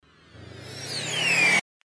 FX-239-WIPE
FX-239-WIPE.mp3